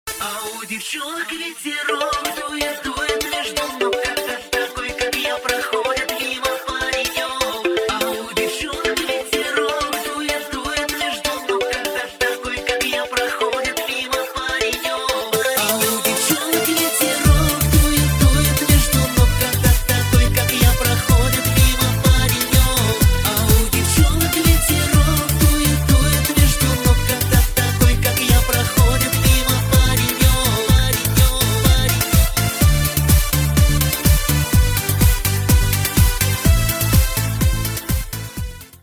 шансон рингтоны